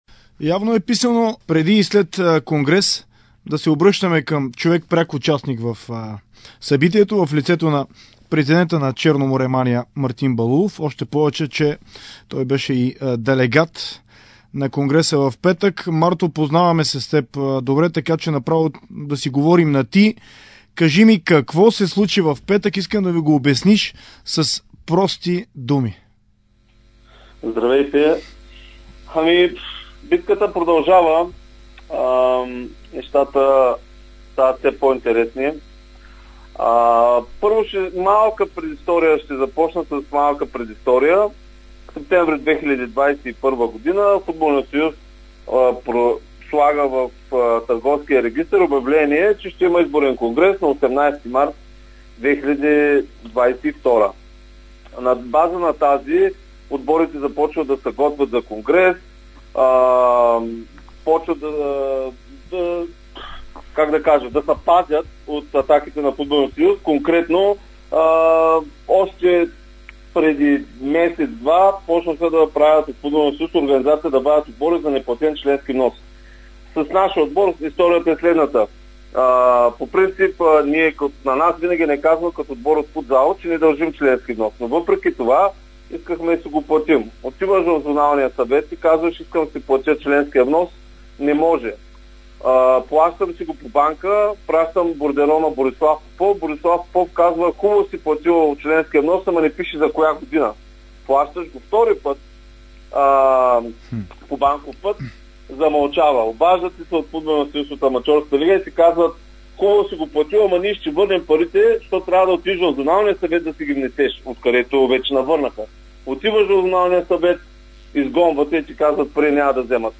разказа любопитни неща в интервю за Дарик радио и dsport. Той разкри за конкретни атаки на футболния съюз срещу клубовете, за които предварително са знаели, че няма да ги подкрепят.